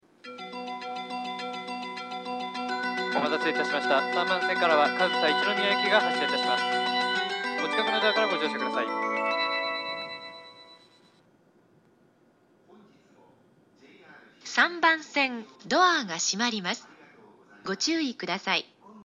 ○発車メロディー○
発車メロディーフルコーラスです。
スピーカーの位置が少し低めだと思います。